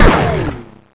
uzi-fire-sil.wav